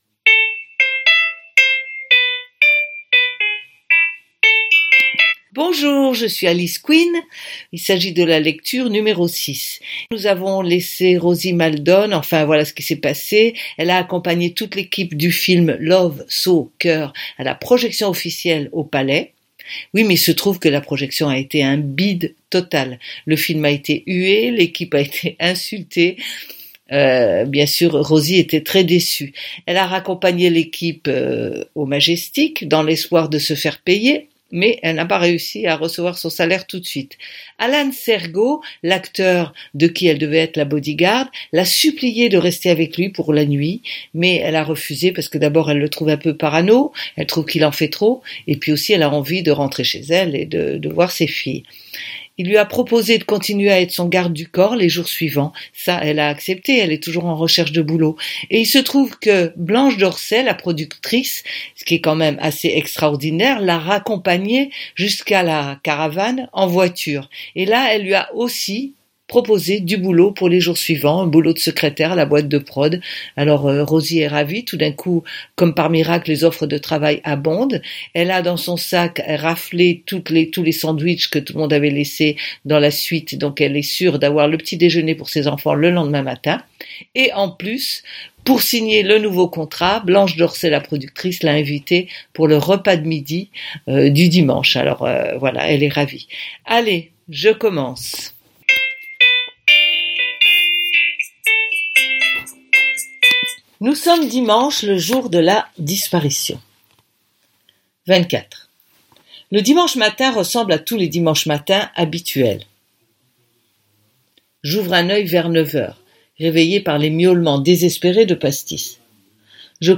Lecture #6 Ce roman fait partie de la série AU PAYS DE ROSIE MALDONNE.